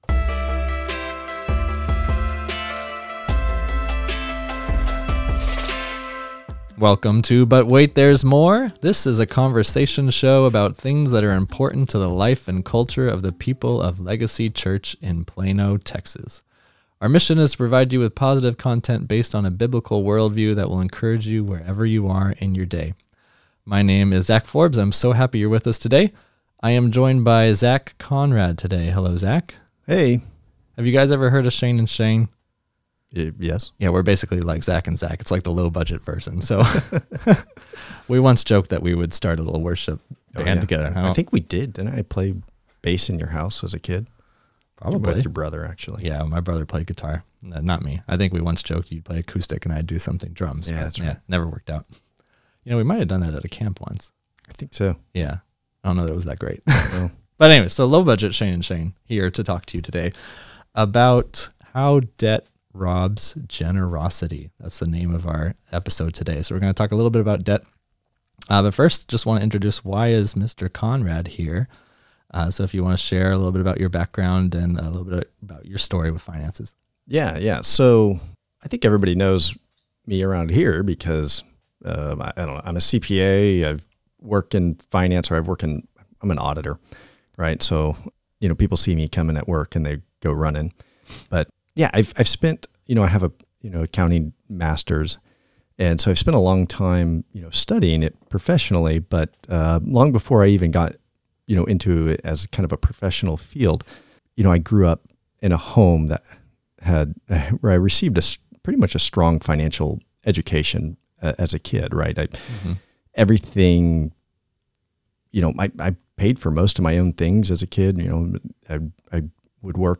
This is a conversation show about things that are important to the life and culture of the people of Legacy Church in Plano, TX.